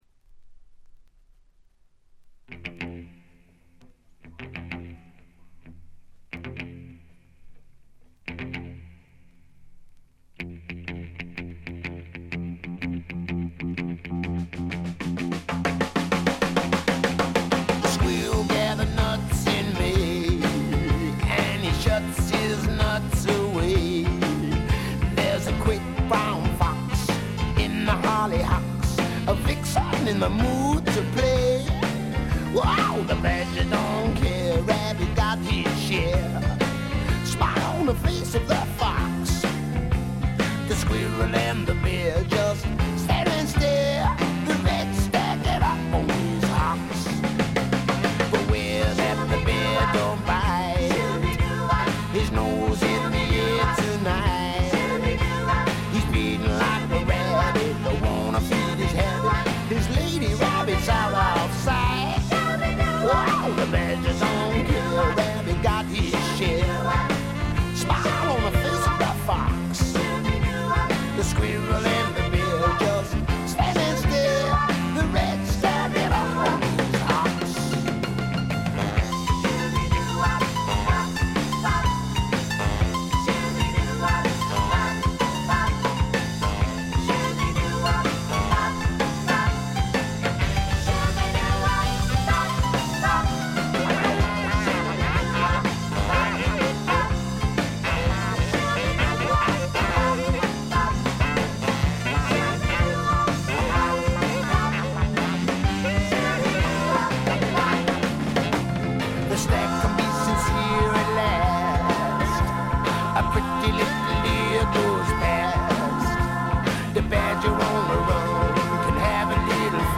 部分試聴ですがチリプチ少々。
後のブロックヘッズのような強烈な音もいいですが、本作のようなしゃれたパブロックも最高ですね！
試聴曲は現品からの取り込み音源です。